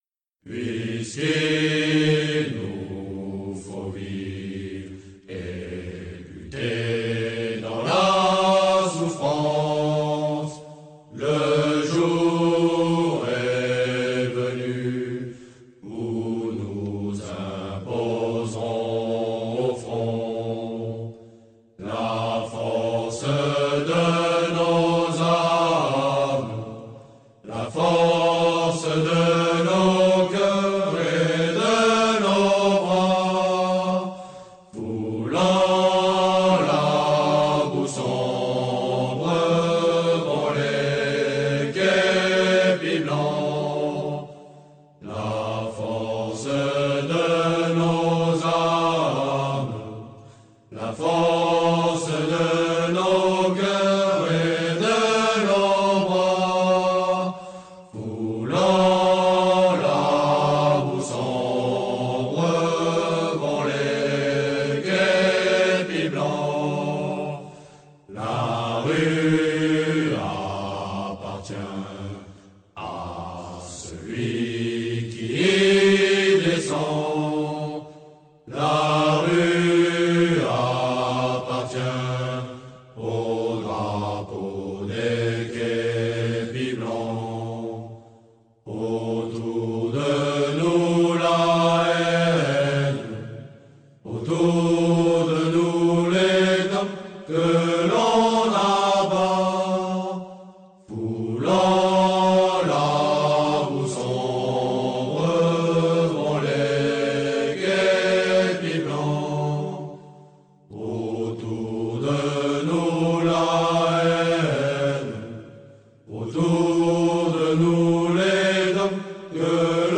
Ils auraient dû entendre ce chant profond et magnifique des képis blancs :